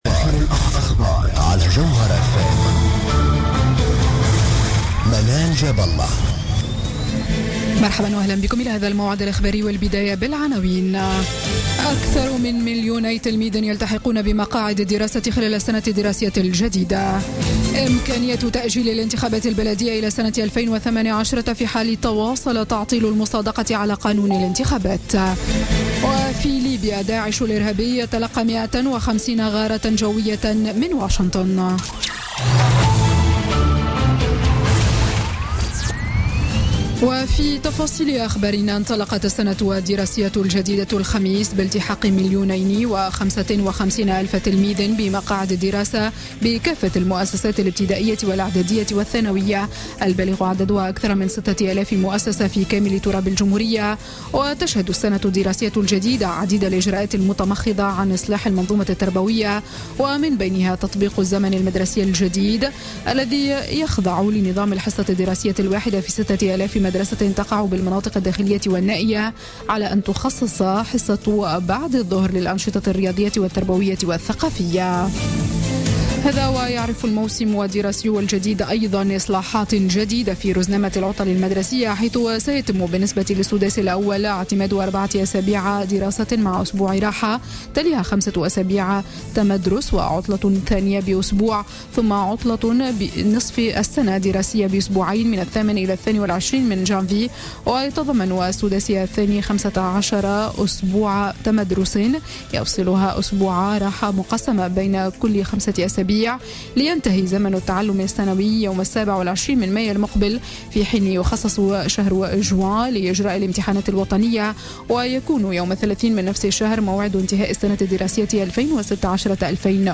Journal Info 00h00 du vendredi 16 septembre 2016